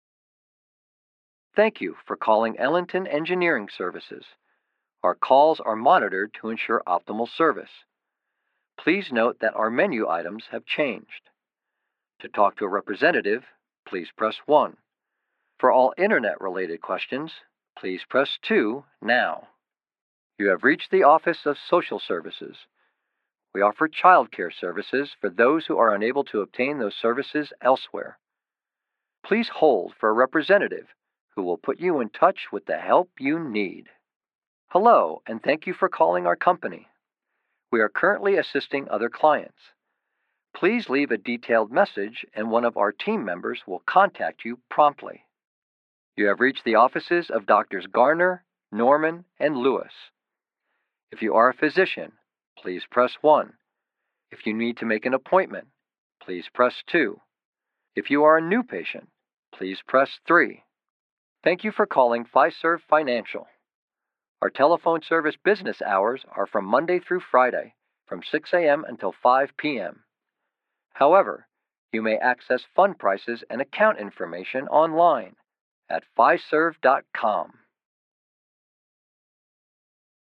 On-Hold Messaging
English - Midwestern U.S. English
Middle Aged
Senior